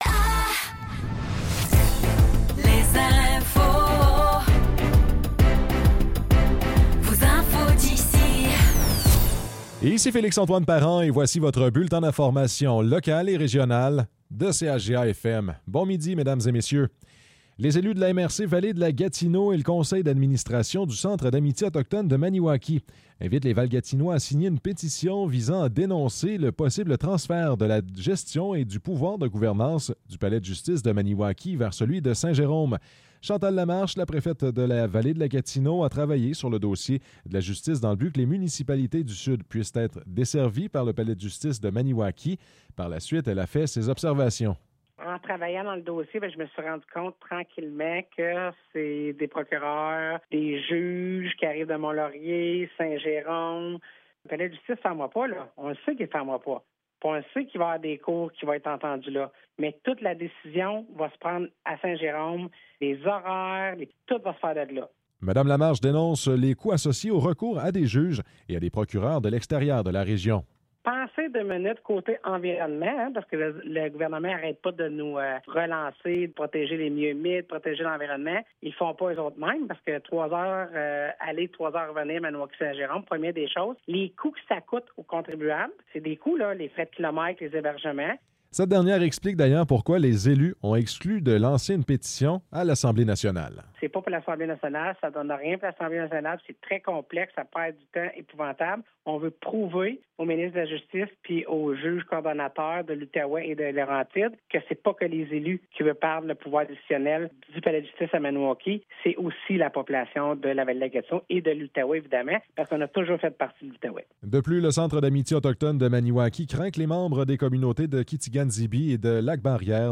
Nouvelles locales - 15 février 2024 - 12 h